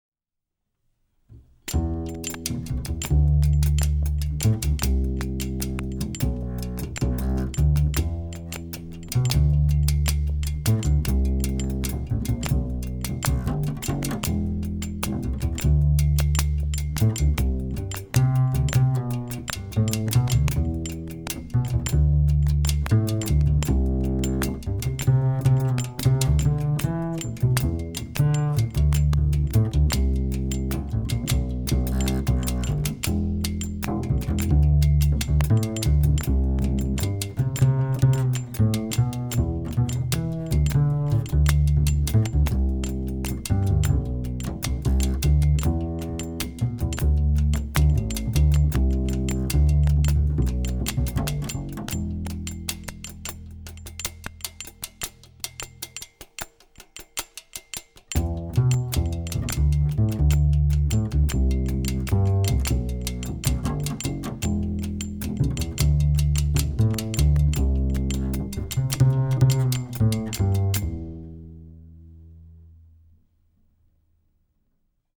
Film music:
- Short bass and percussion theme (2009) (1:15)